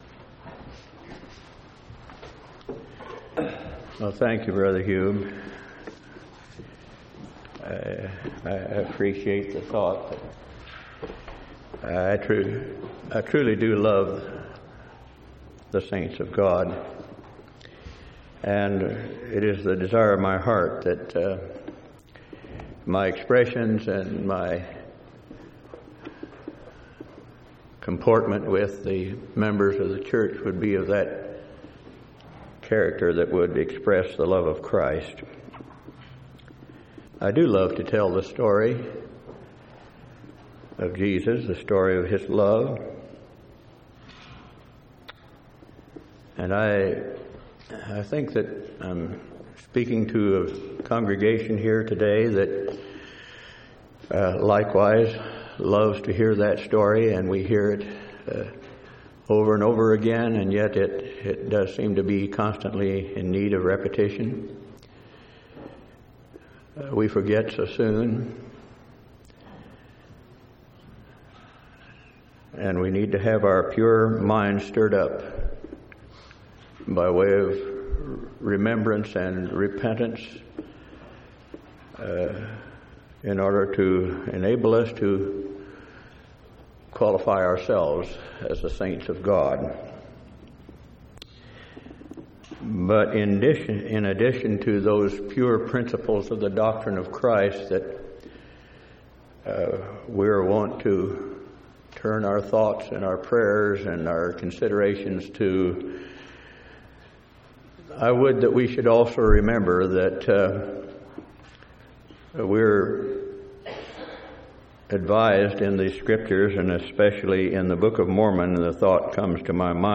4/9/1997 Location: Temple Lot Local (Conference) Event: General Church Conference